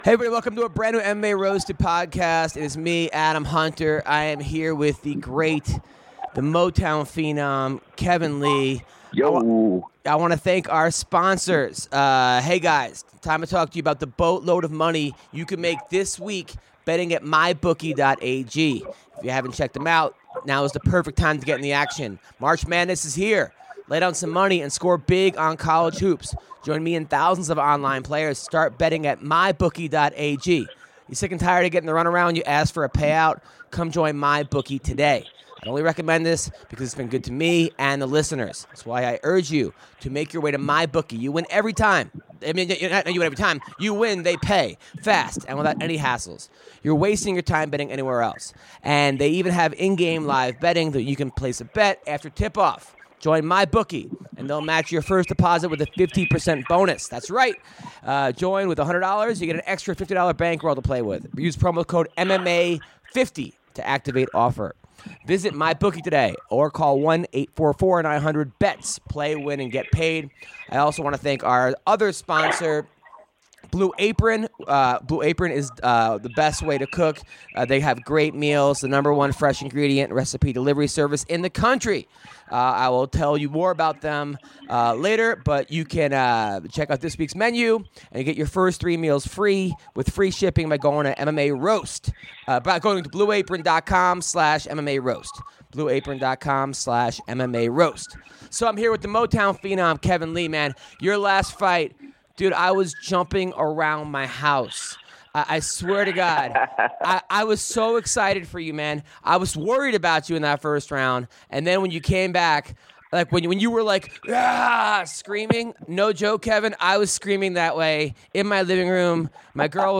In this episode of the MMA Roasted Podcast, Kevin Lee calls in to discuss his recent victory as well as his future in the UFC.